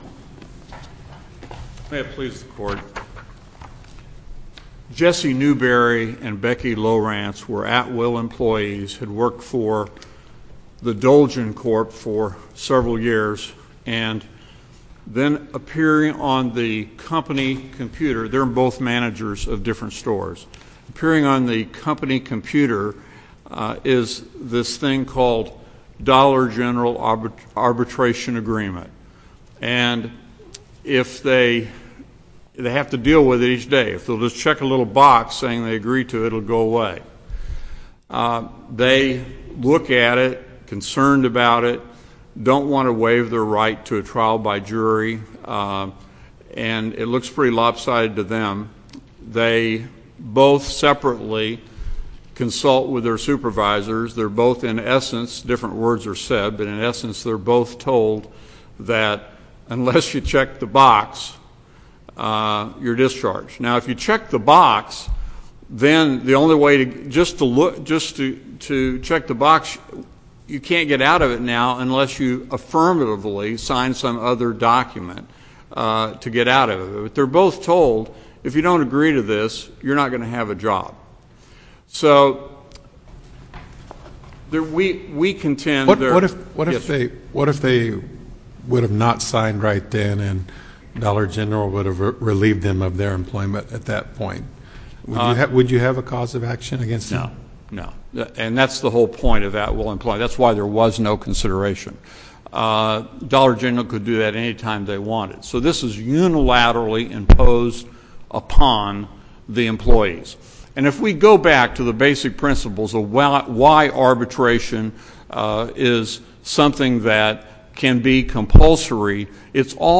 link to MP3 audio file of oral arguments in SC97018